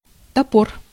Ääntäminen
IPA: /aʃ/